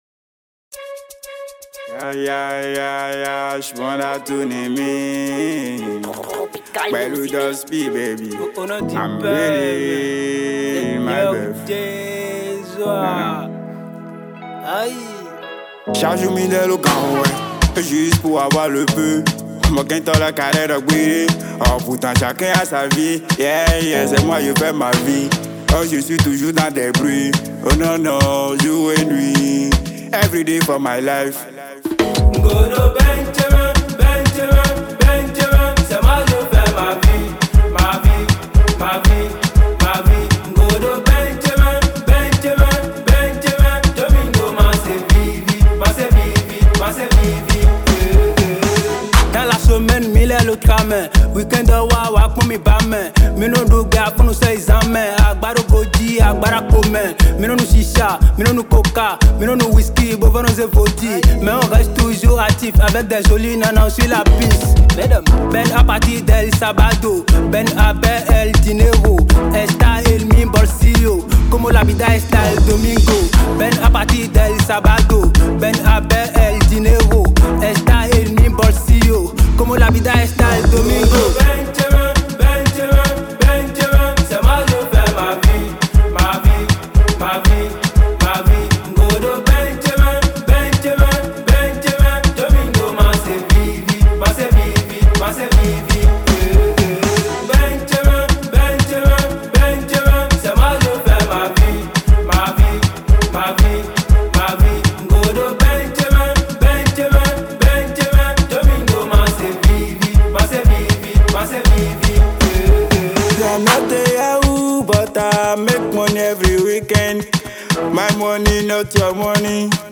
Urban Mp3